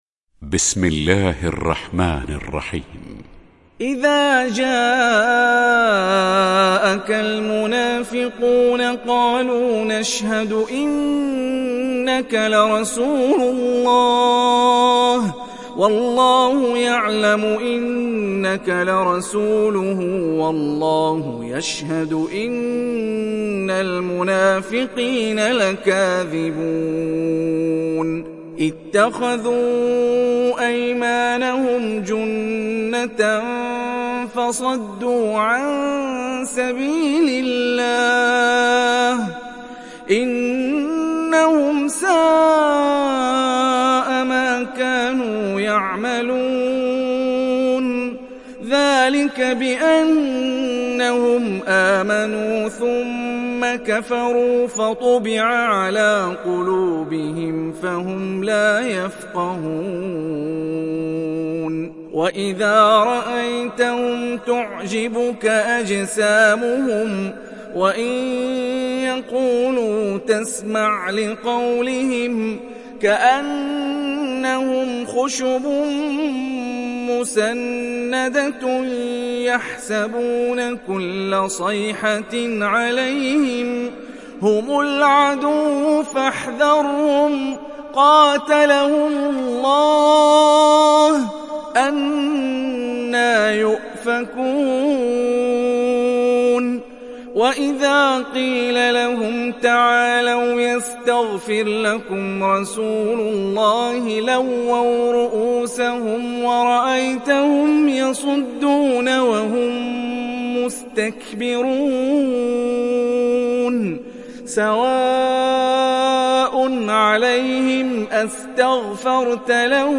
دانلود سوره المنافقون mp3 هاني الرفاعي (روایت حفص)